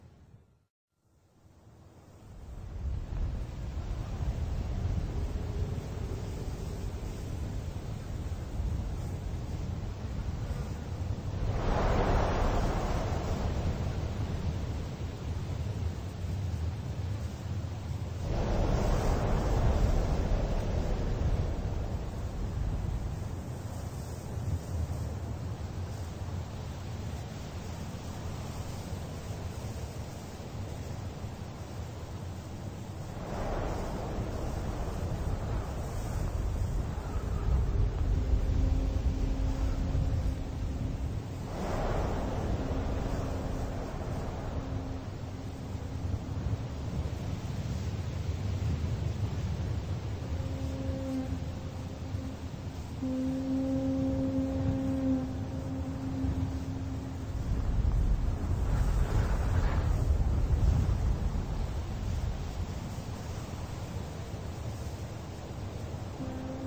Звуки ветра в пустыне
Ветер в пустыне с сильными порывами